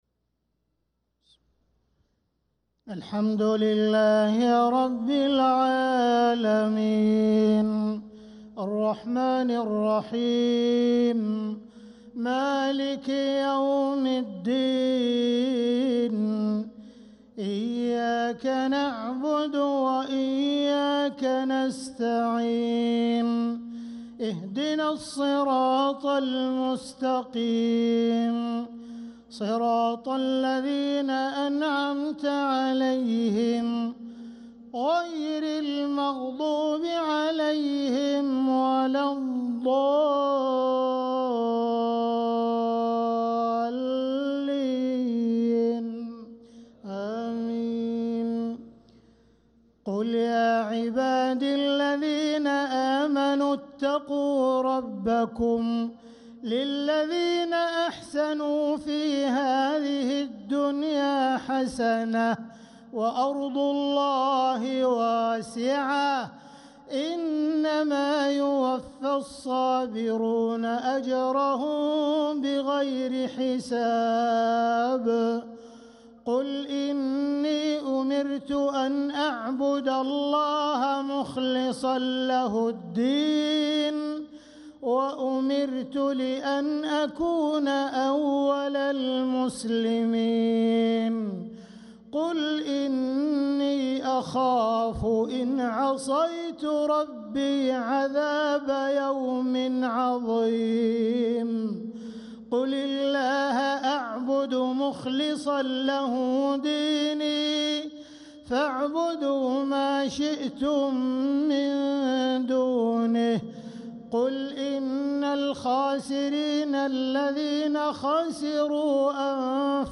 صلاة العشاء للقارئ عبدالرحمن السديس 16 جمادي الأول 1446 هـ
تِلَاوَات الْحَرَمَيْن .